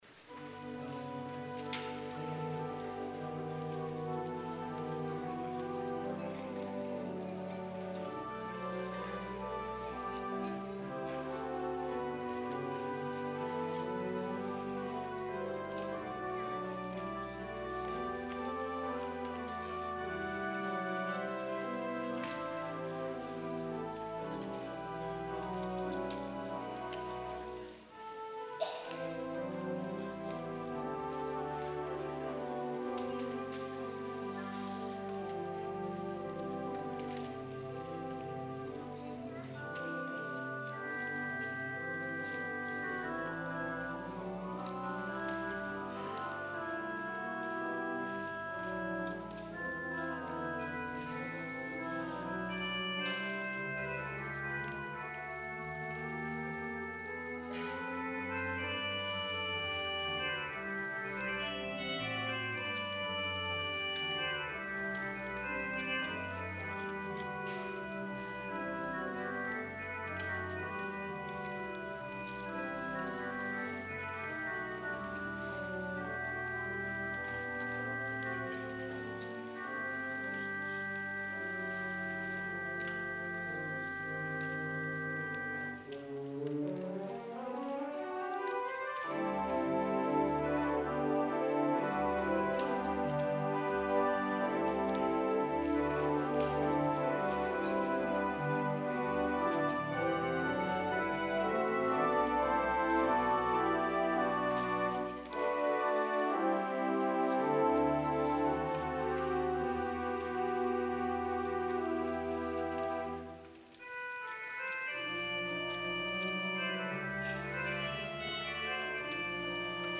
Chorale Prelude on “Douglass”
organ